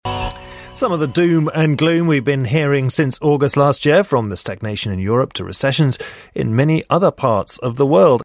在线英语听力室【英音模仿秀】经济学家的“水晶球”的听力文件下载,英音模仿秀—英语听力—听力教程—在线英语听力室